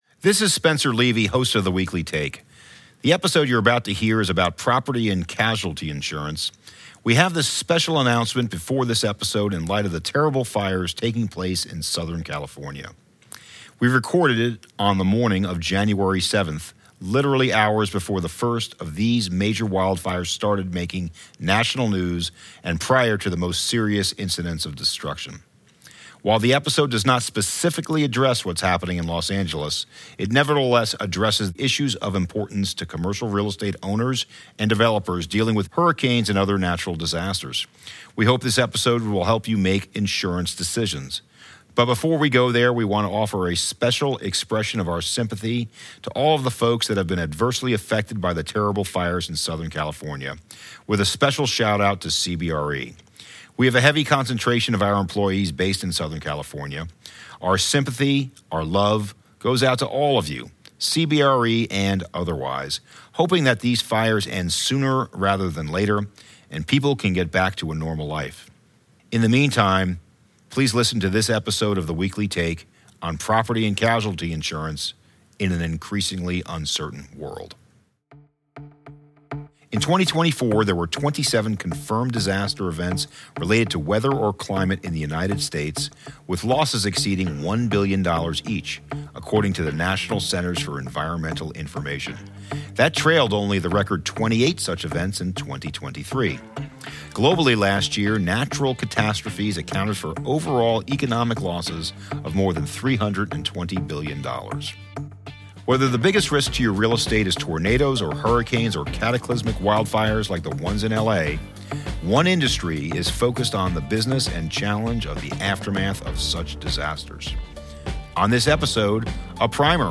What matters most right now in Commercial Real Estate. Business leaders join economic, industry and subject matter experts to share their distinct views and latest thinking.